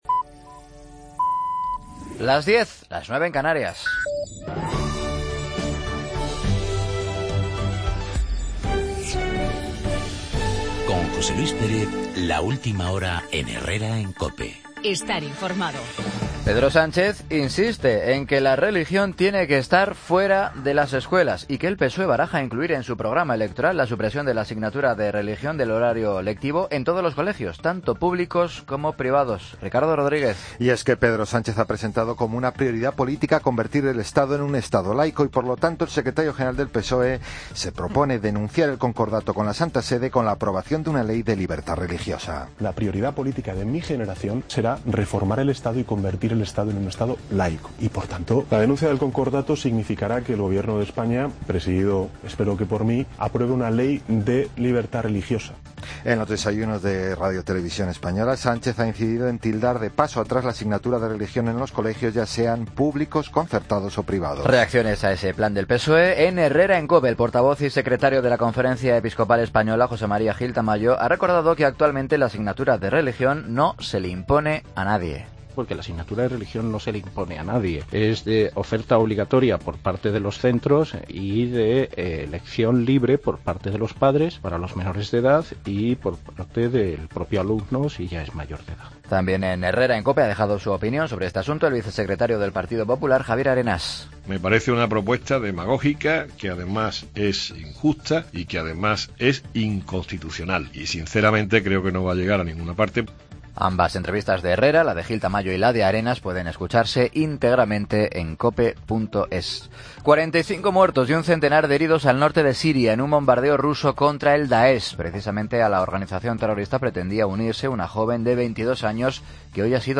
Noticias de las 10.00 horas, martes 20 de octubre de 2015